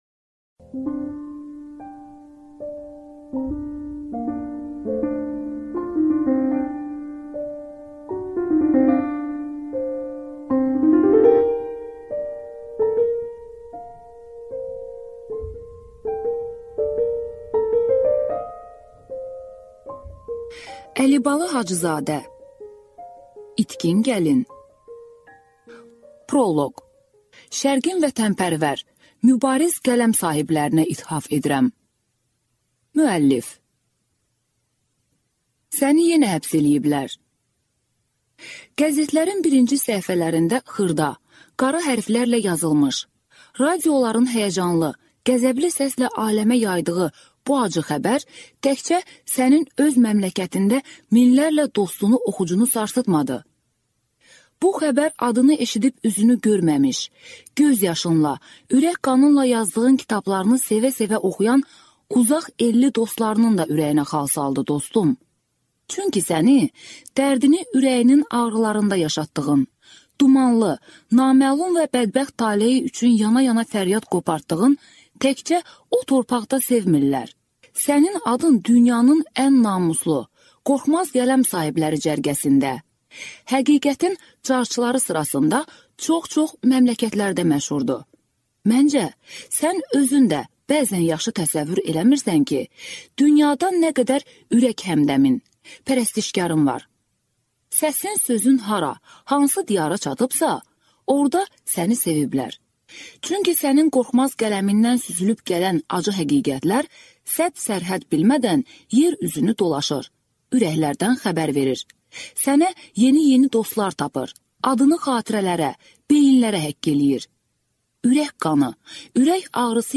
Аудиокнига İtkin gəlin | Библиотека аудиокниг